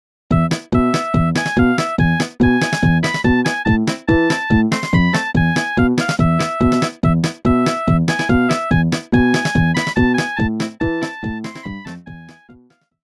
Nokia полифония. Народные